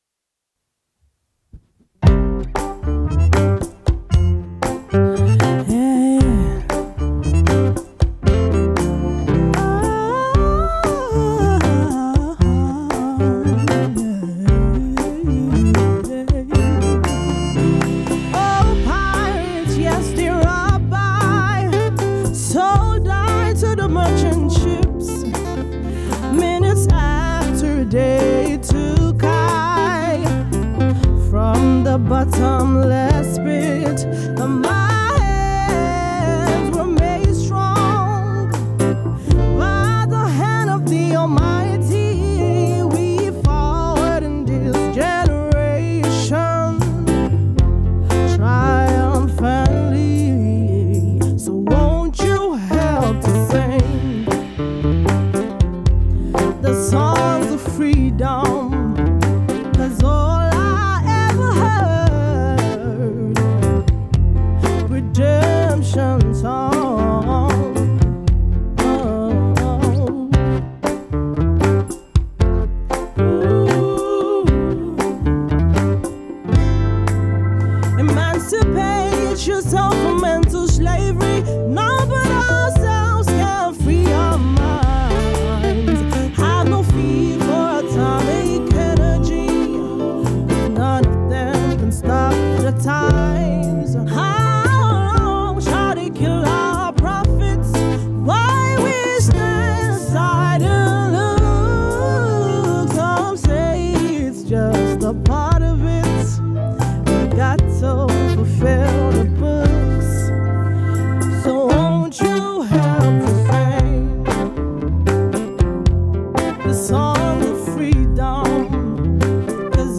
with a soulful twist